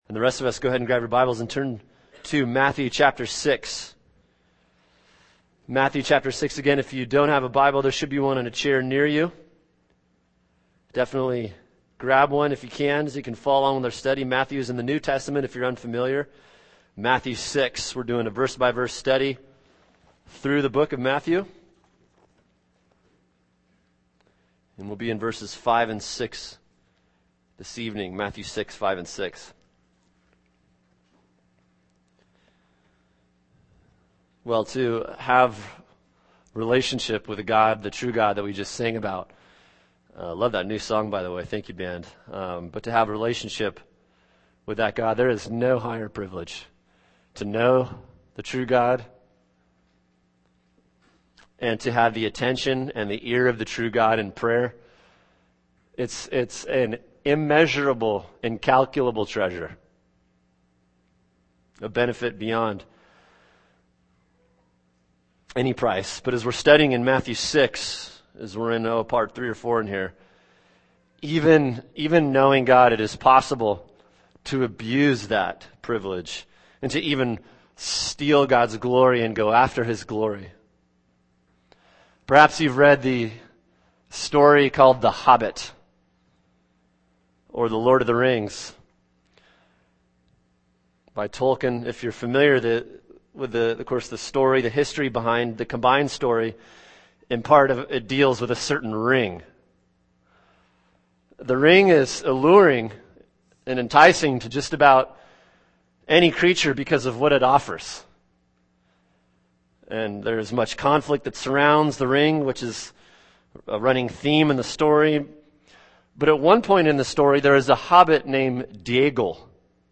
[sermon] Matthew 6:5-6 “Religiatrics and Prayer” | Cornerstone Church - Jackson Hole